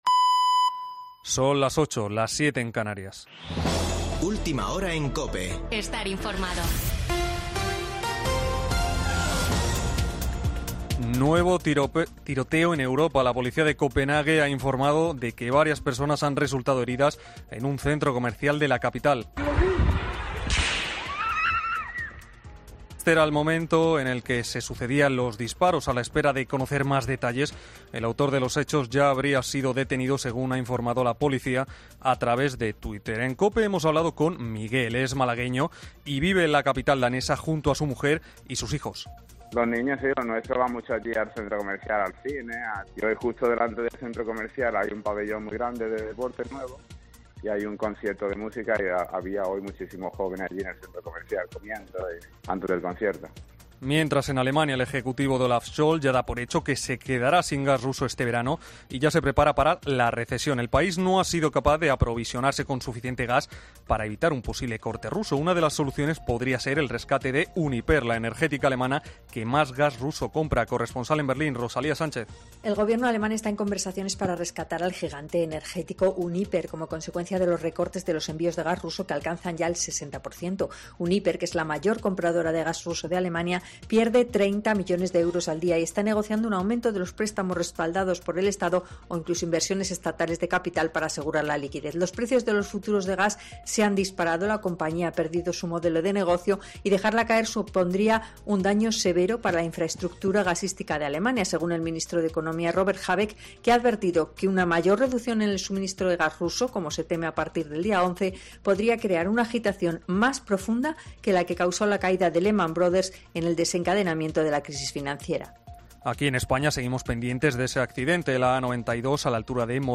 Boletín de noticias de COPE del 3 de julio de 2022 a las 20.00 horas